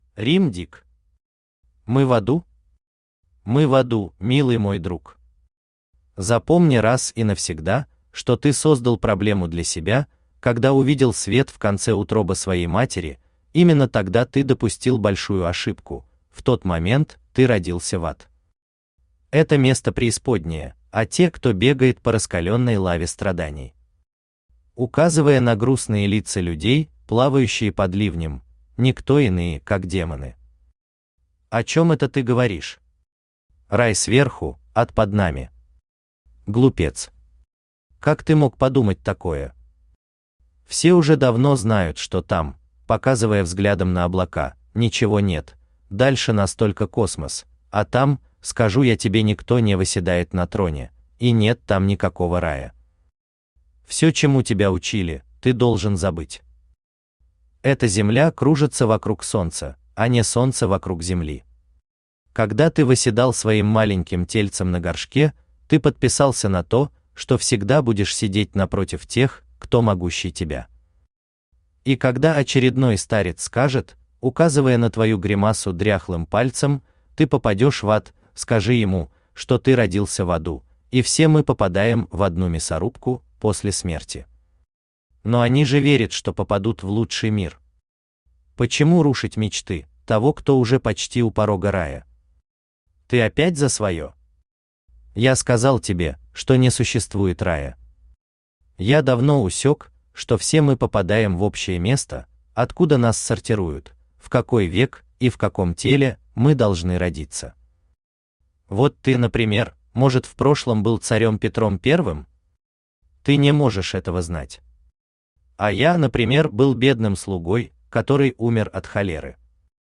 Аудиокнига Мы в аду?
Автор Рим Дик Читает аудиокнигу Авточтец ЛитРес.